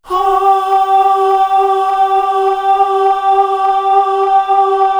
Index of /90_sSampleCDs/Best Service ProSamples vol.55 - Retro Sampler [AKAI] 1CD/Partition C/CHOIR AHH